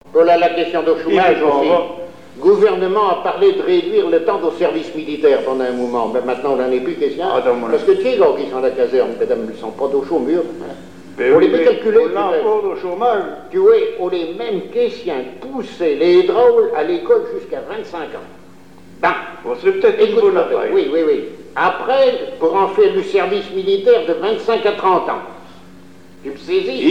Alouette FM numérisation d'émissions par EthnoDoc
Catégorie Témoignage